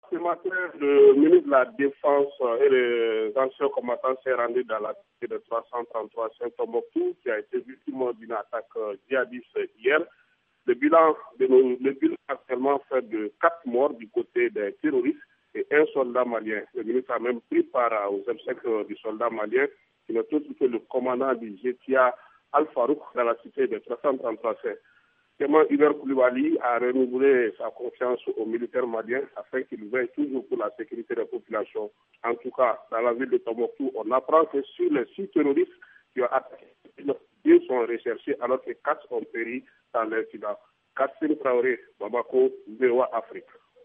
Le reportage